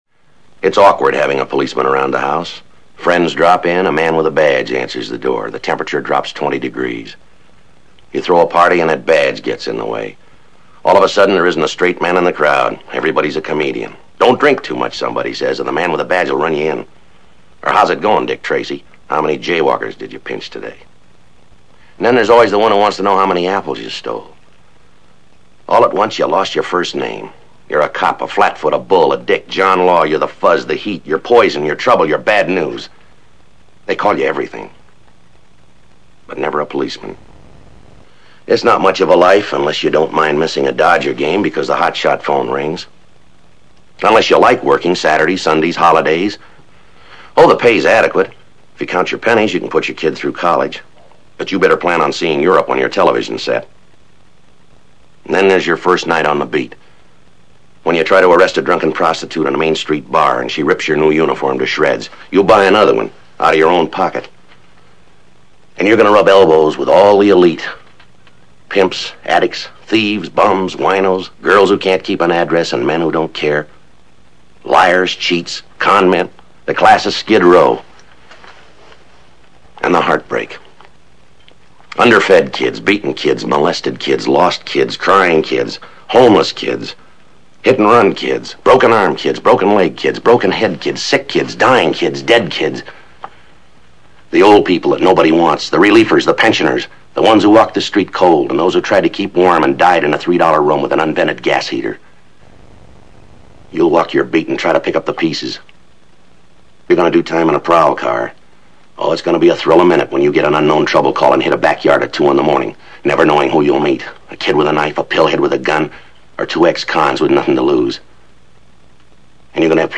Sgt. Joe Friday Speech